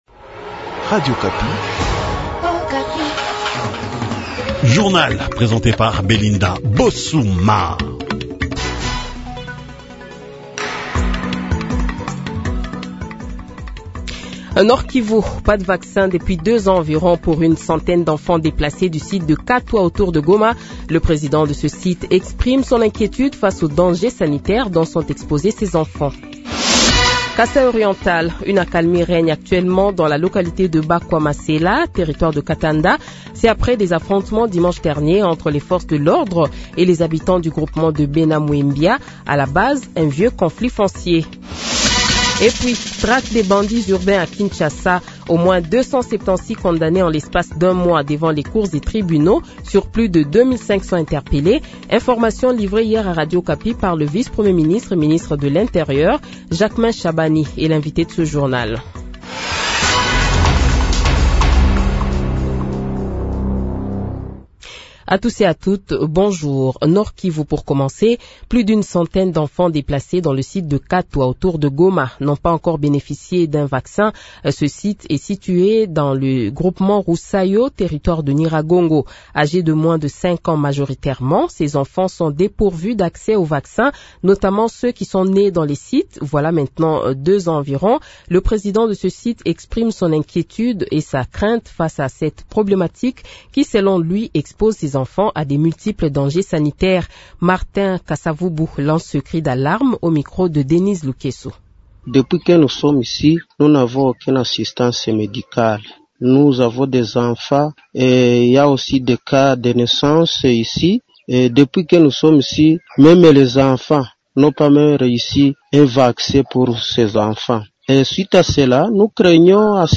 Le Journal de 8h, 08 Janvier 2025 :